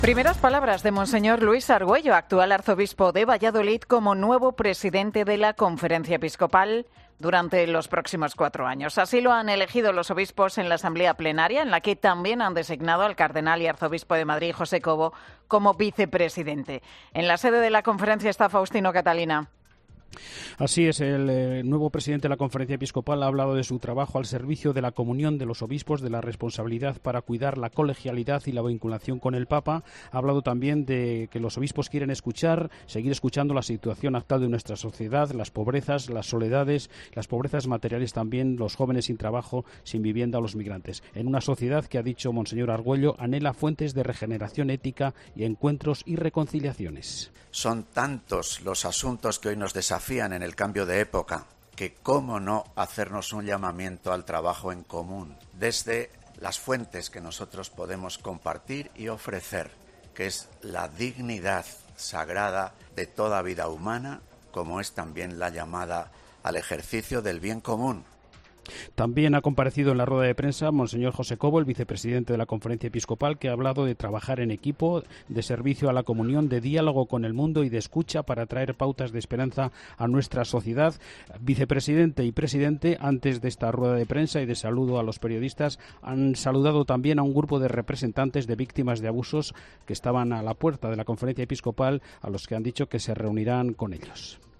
Luis Argüello y José Cobo han comparecido tras ser elegidos presidente y vicepresidente de la CEE, en la que destacan la comunión entre los obispos: "No hay bandos distintos"